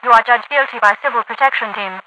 File usage The following page links to this file: Overwatch Voice/Quotes
Youarejudgedguilty_ovoice.ogg